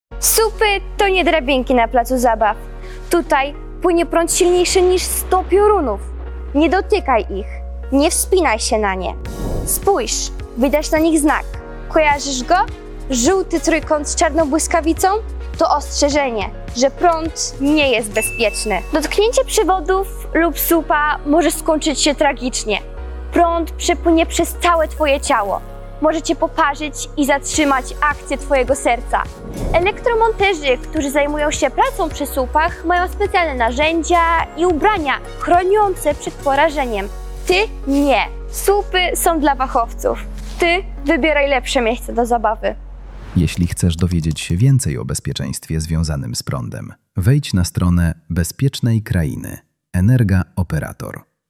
Słupy energetyczne to nie element placu zabaw. Dzieci tłumaczą dzieciom, dlaczego lepiej trzymać się od nich z daleka.